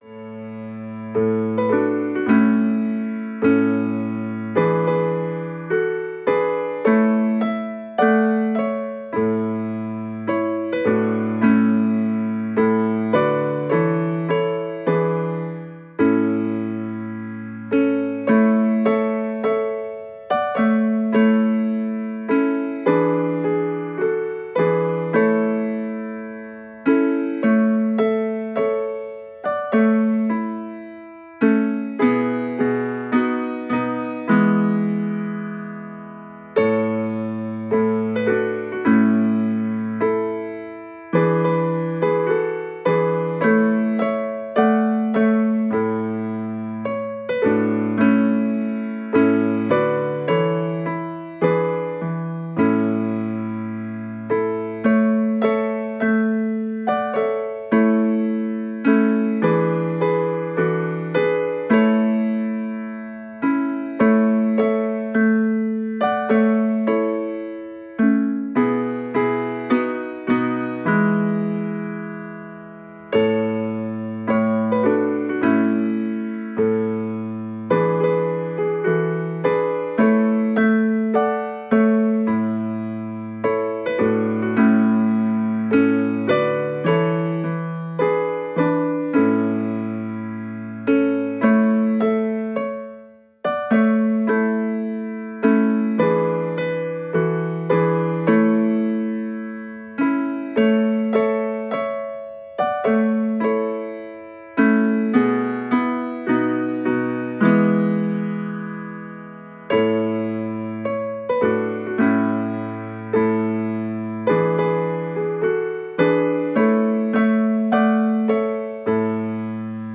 chants traditionnels
interprétés dans une atmosphère chaleureuse et raffinée.